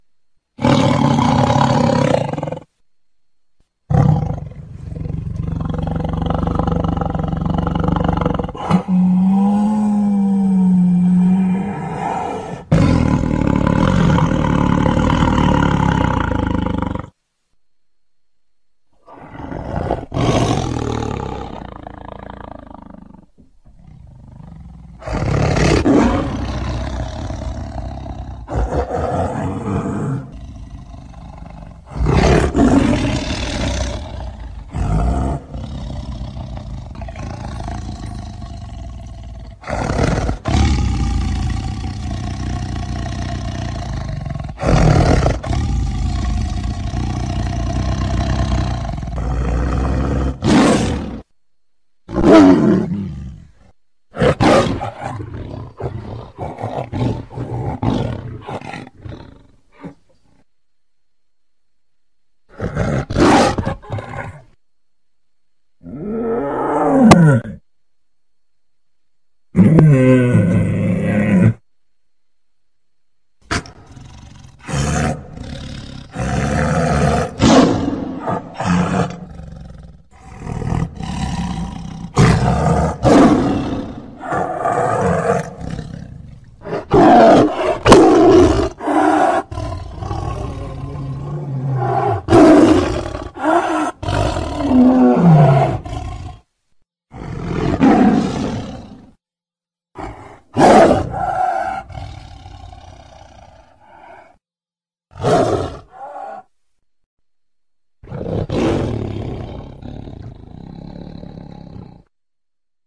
Suara Harimau Marah
Kategori: Suara binatang liar
Keterangan: Dengarkan kekuatan alam melalui suara harimau marah (Tiger Roar) yang menggelegar! Suara mengaum ini membawa sensasi kehebatan dan ketegangan dari raja hutan.
suara-harimau-marah-id-www_tiengdong_com.mp3